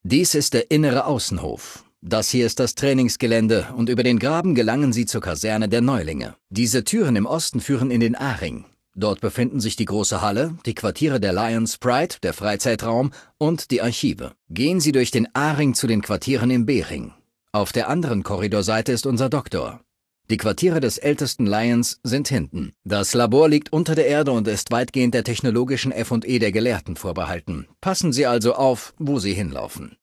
Datei:Maleadult01default dialogueci citknightdirect 00026c43.ogg
Fallout 3: Audiodialoge